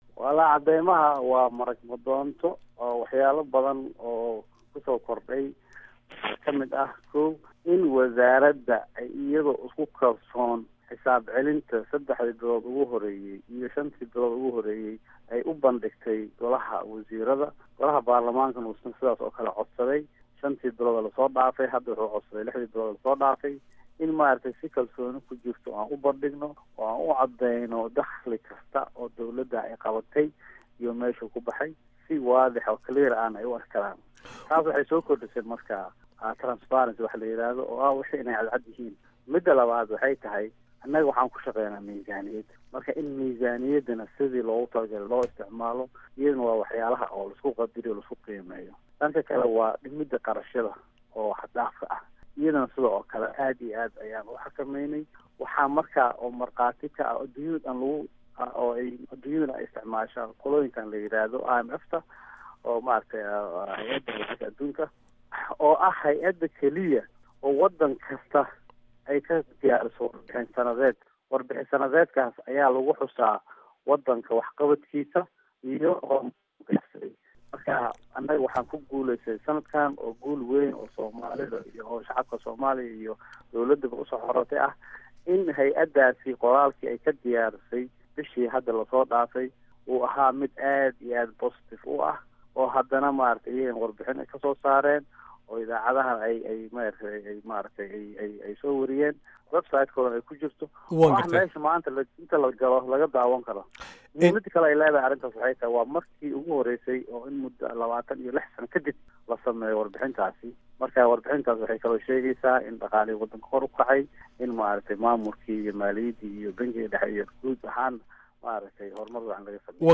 Wasiirka Maaliyadda Soomaaliya Maxamd Aadan Farageeti oo wareysi siiyey VOA-da, ayaa sheegay in hannaankan cusub uu ka hortagayo in musuq-maasuq ama wax is-daba marin maaliyadeed la sameeyo.
Wareysi: Maxamed Aadan Fargeeti